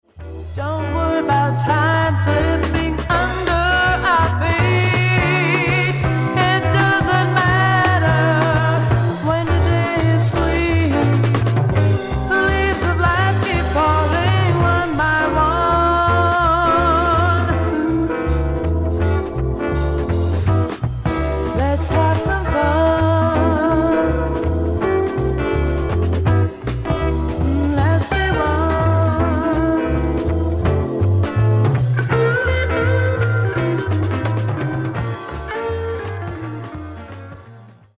SOFT ROCK / PSYCHEDELIC POP